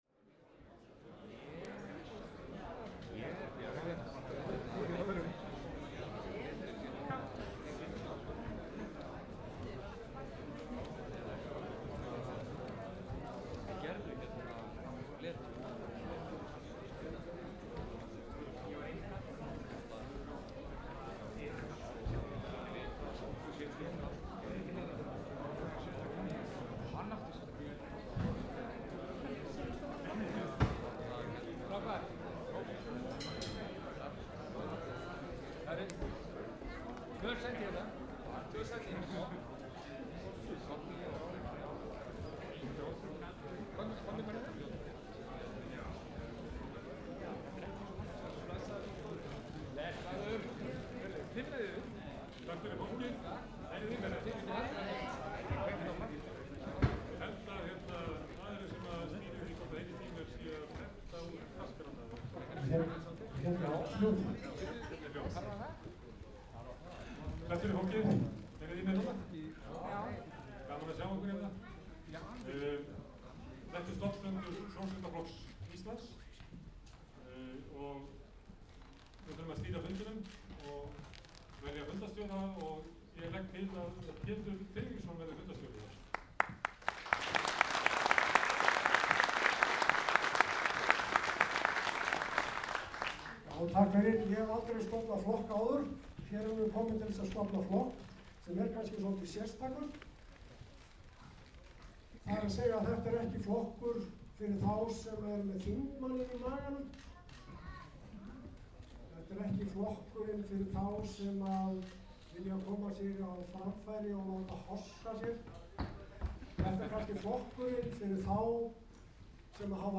Meðfylgjandi upptakan er frá stofnfundi Sósialistaflokksins í Tjarnarbíói. Troðfullt var út að dyrum og góðs stemning.
Following recording is from the establishment, (or restoration), of the Socialist party of Iceland, 1st. of May 2017.